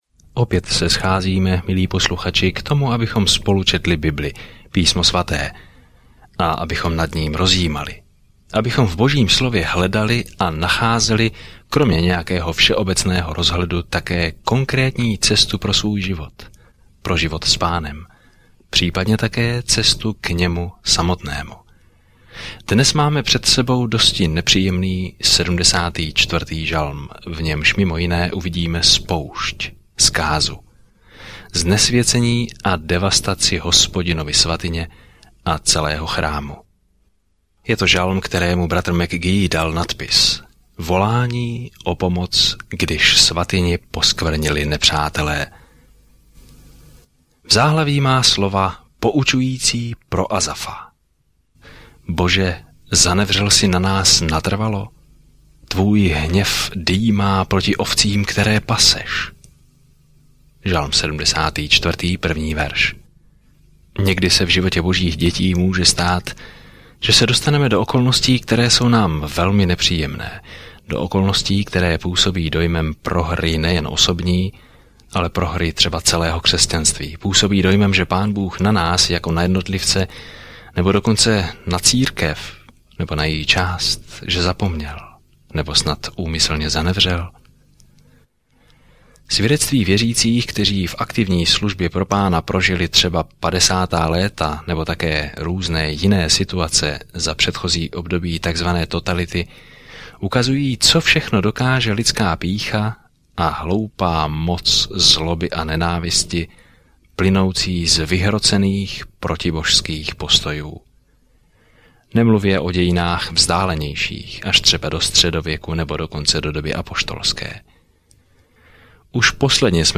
Denně procházejte žalmy, poslouchejte audiostudii a čtěte vybrané verše z Božího slova.